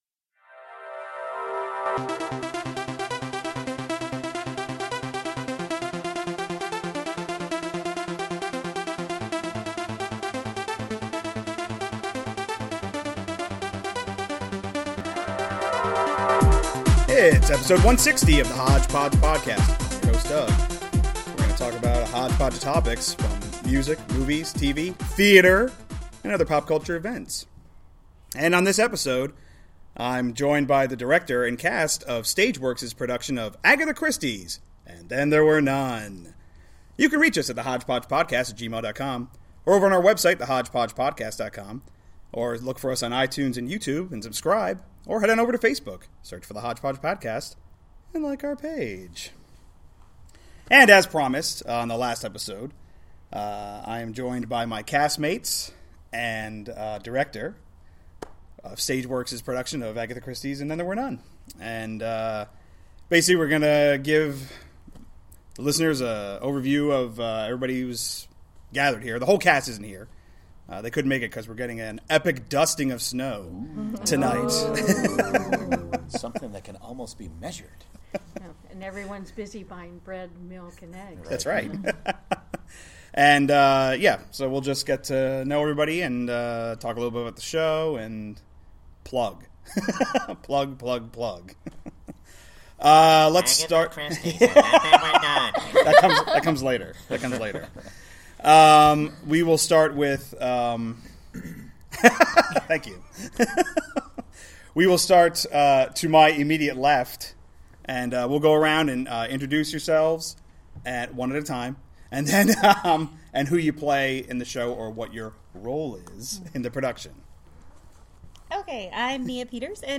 interviewing his director and some of his cast mates